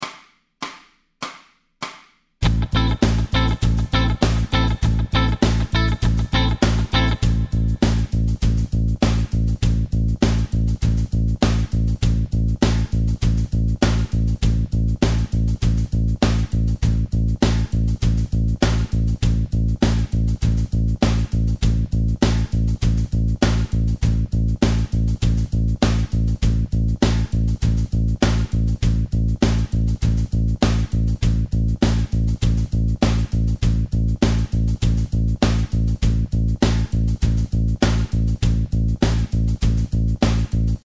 Vasaku käega summutame kõik keeled, parem käsi liigub hoogsalt alla-üles.
Harjutused 3–5 on samuti üsna lihtsad – akord tuleb alla vajutada vastavalt iga löögi 2.-l, 3.-l või 4.-l veerandil, parema käe töö jääb samaks.